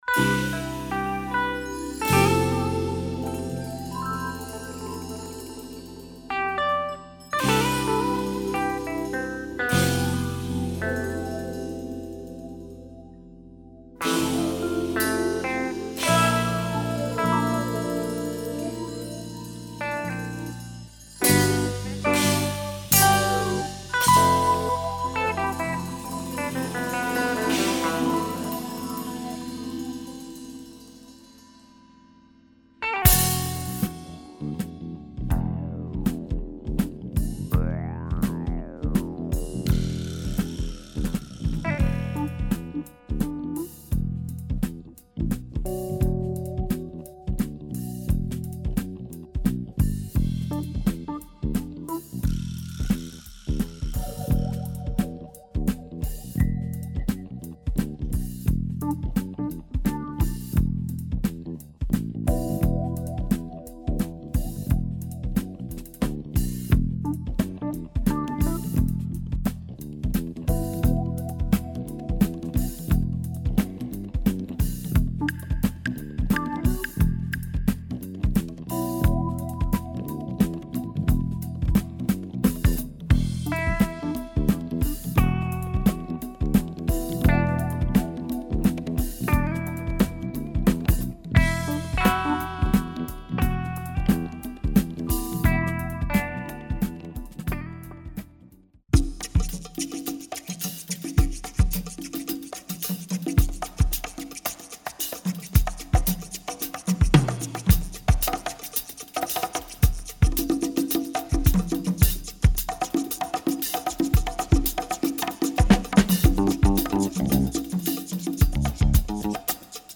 Euro jazz / jazz funk session
bass
keyboards